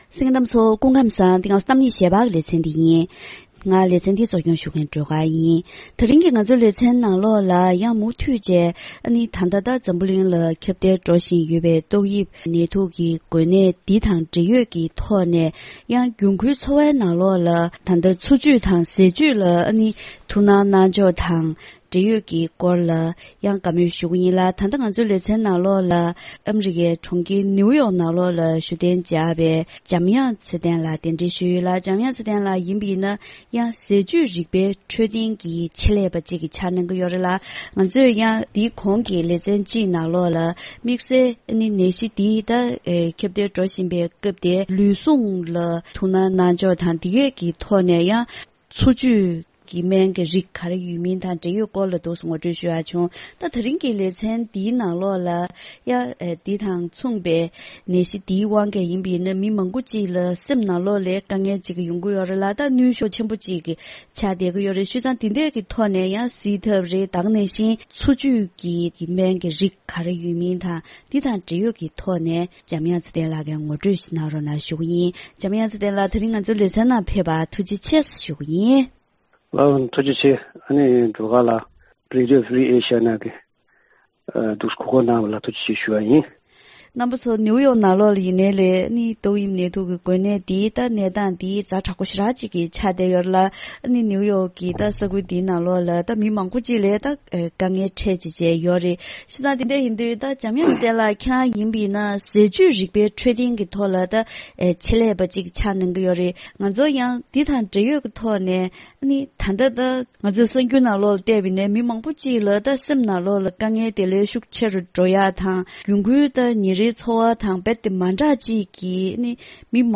ད་རིང་གི་གཏམ་གླེང་ཞལ་པར་ལེ་ཚན་ནང་ཏོག་དབྱིབས་ནད་དུག་གི་འགོས་ནད་འཛམ་གླིང་ནང་ཁྱབ་གདལ་འགྲོ་བཞིན་ཡོད་པའི་སྐབས་དེར་སེམས་ནང་གི་དངངས་སྐྲག་ཆུང་དུ་གཏོང་ཐབས་དང་ལུས་ཟུངས་ཞན་པའི་མི་ཚོས་ཟས་བཅུད་ལ་དོ་སྣང་ཇི་ལྟར་དགོས་ཚུལ་སོགས་གྱི་སྐོར་ལ་ངོ་སྤྲོད་ཞུས་པ་ཞིག་གསན་རོགས་གནང་།